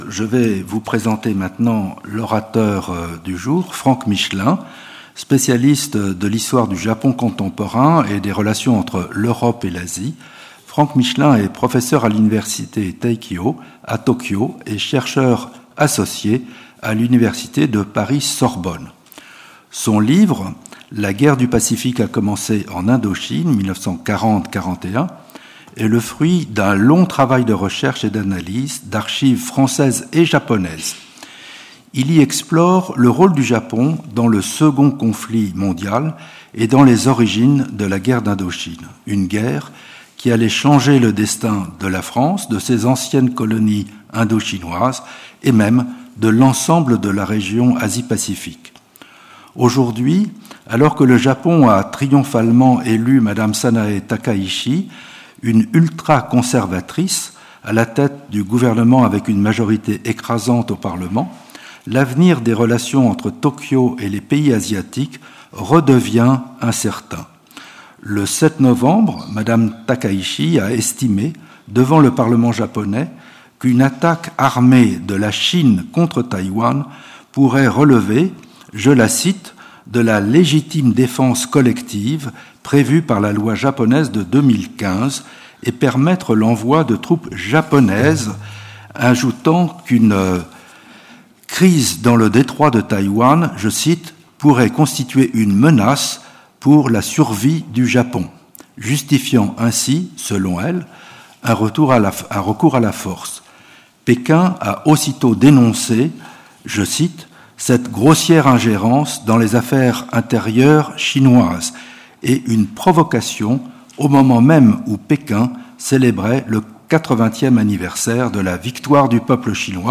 Dans son intervention devant l’Académie, Jérôme Bonnafont interroge la résilience de l’ordre international issu de la Charte de San Francisco (1945) face à la résurgence contemporaine des nationalismes et des logiques de puissance. Le diplomate dresse le constat d’une fracture majeure : le passage d’un système aspirant à l’universalité du droit et à la sécurité collective vers une fragmentation géopolitique menacée par l’anarchie ou une bipolarité sino-américaine rigide.